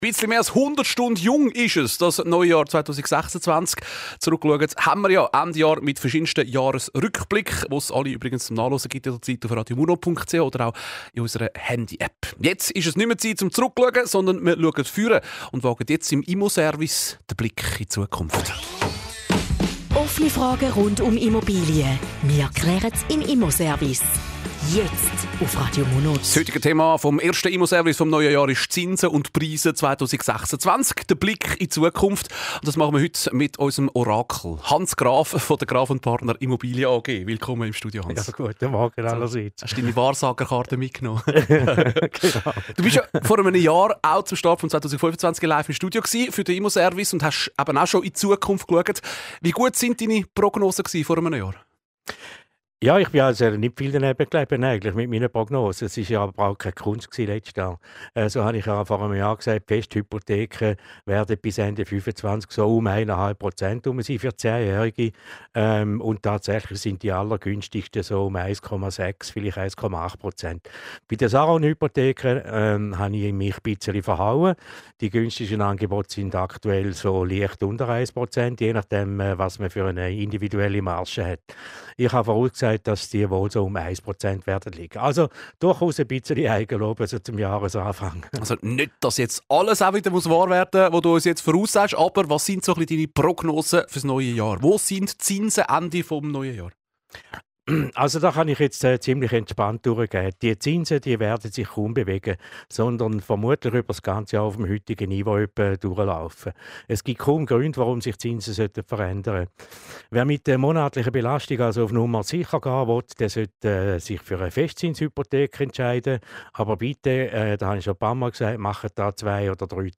Zusammenfassung des Interviews zum Thema "Prognosen und Zinsen":